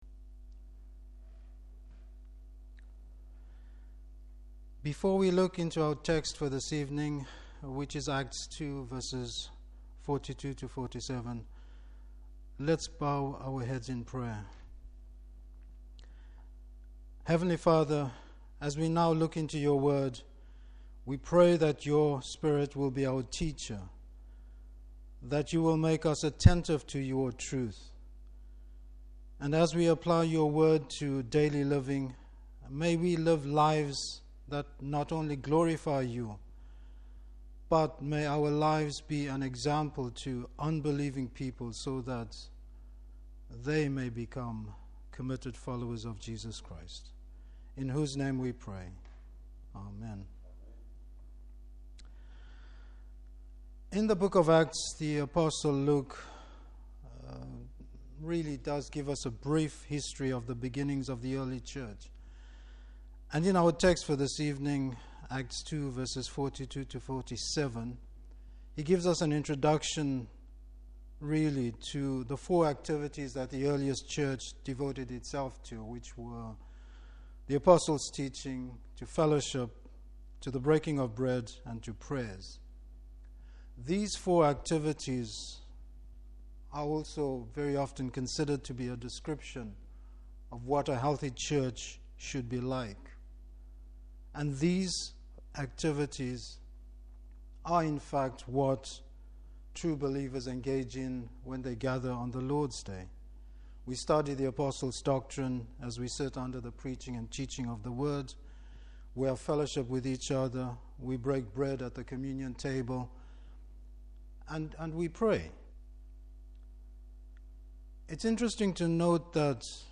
Service Type: Evening Service What we can learn about fellowship in the early Church.